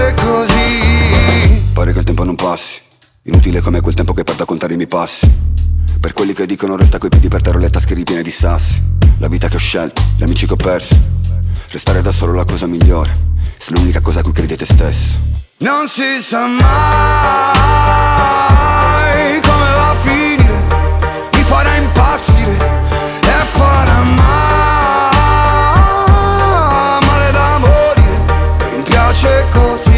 Скачать припев
2025-05-09 Жанр: Поп музыка Длительность